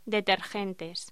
Locución: Detergentes